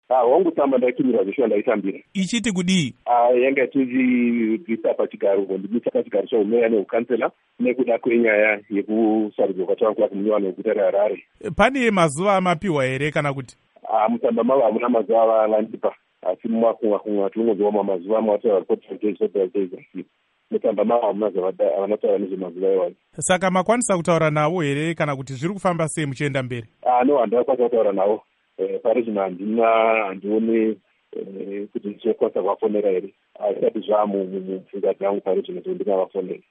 Huruluro naVaBernard Manyenyeni